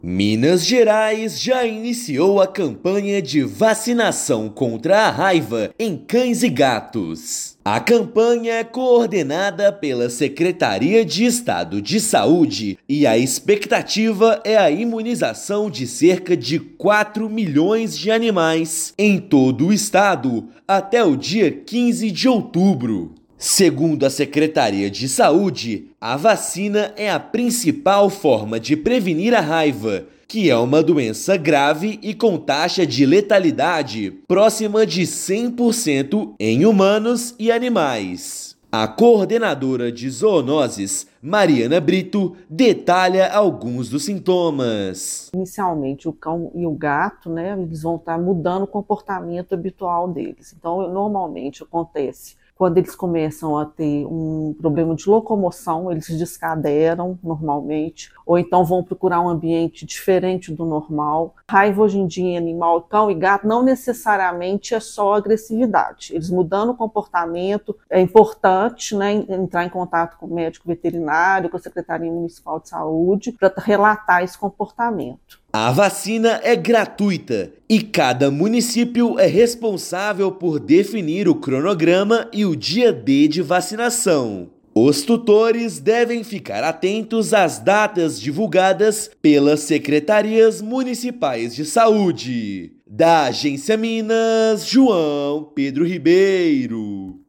Secretaria de Estado de Saúde coordena a ação em todo o estado; mais de 4 milhões de animais devem ser imunizados nos 853 municípios. Ouça matéria de rádio.